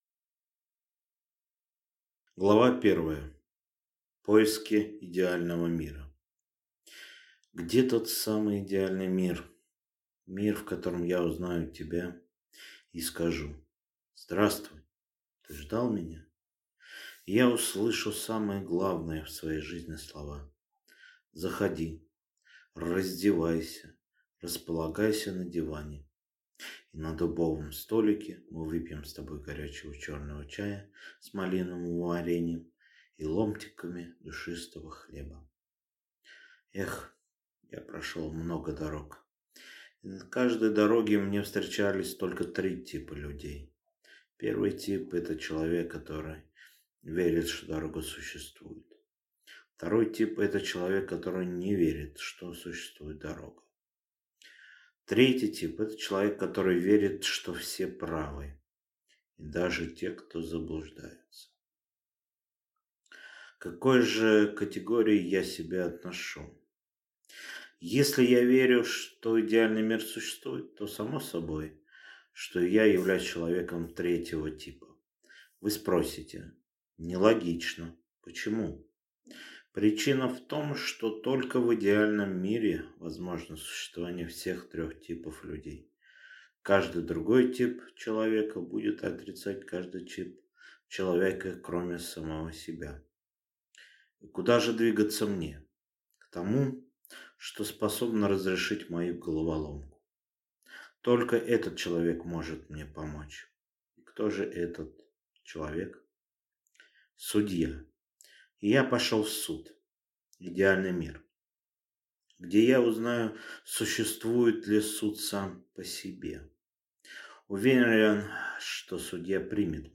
Аудиокнига Суд | Библиотека аудиокниг